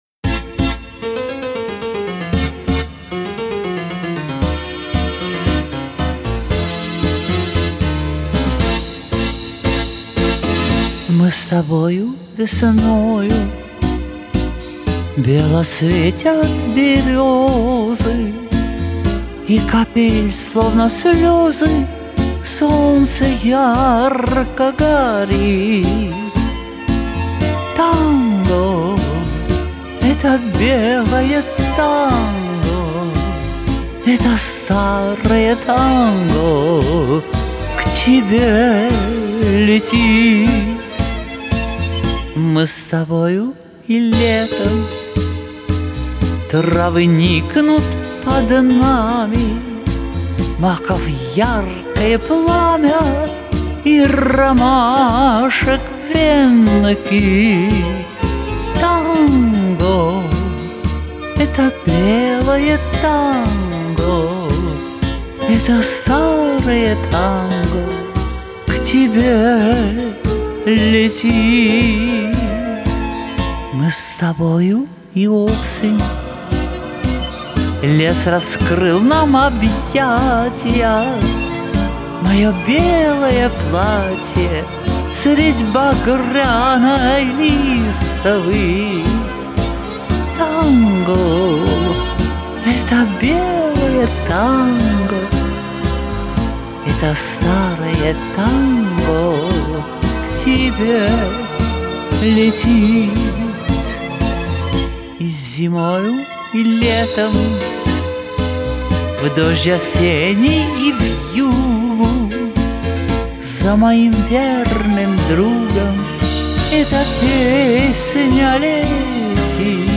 Жанр: Романс